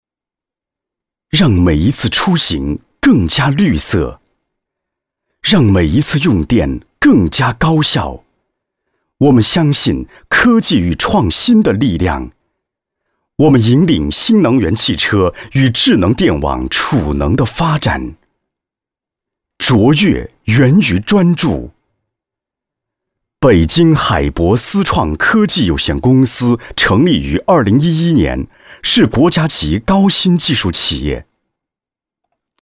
产品解说男36号
成熟稳重 产品解说
青年男配音，时尚年轻，大气稳重。